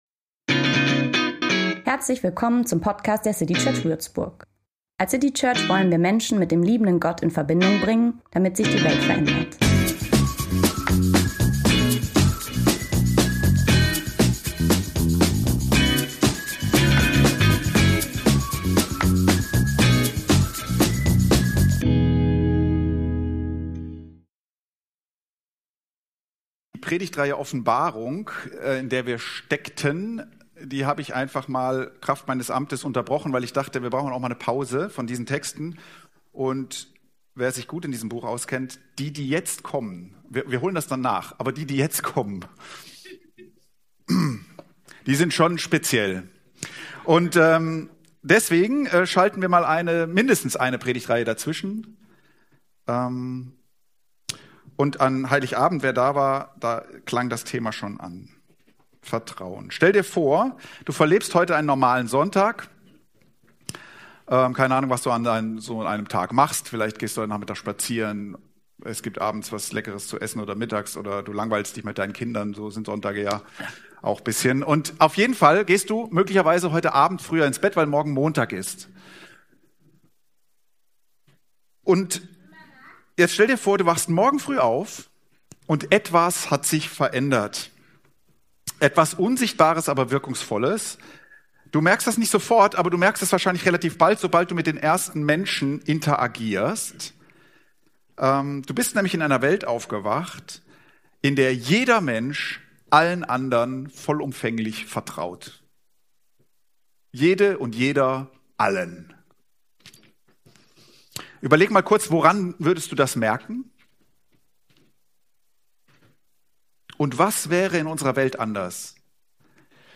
Anregungen und Fragen zur Predigt für dich oder deine Kleingruppe: 2026-01-11 - Auf dünnem Eis.pdf Hilfe!